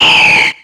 Cri d'Altaria dans Pokémon X et Y.